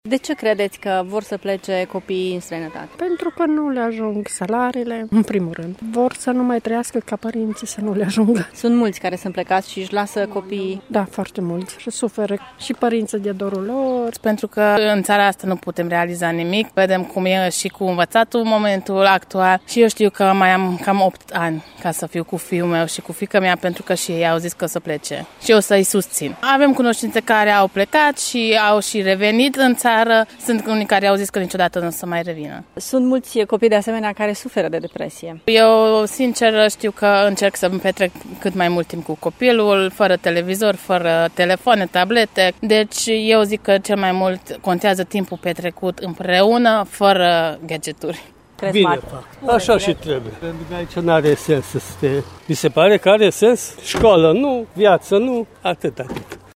Părinții și bunicii din Târgu Mureș susțin și justifică plecarea copiilor lor în străinătate prin numeroasele incertitudini din România: